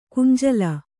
♪ kunjala